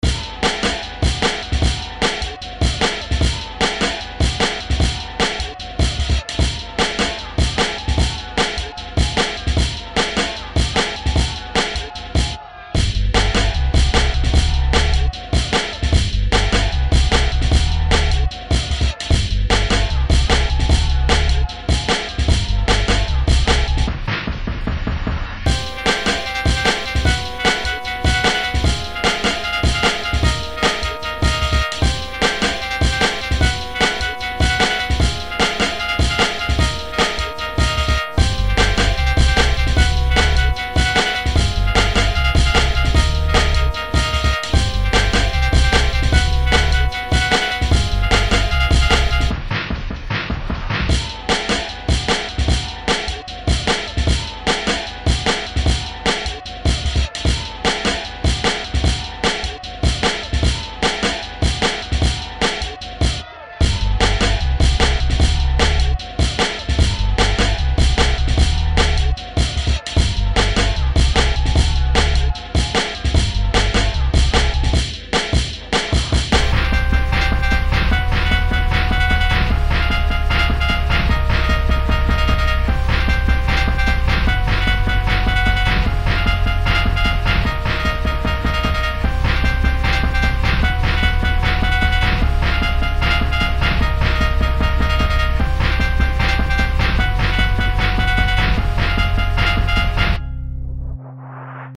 Frantic bassy breakbeat for something hectic.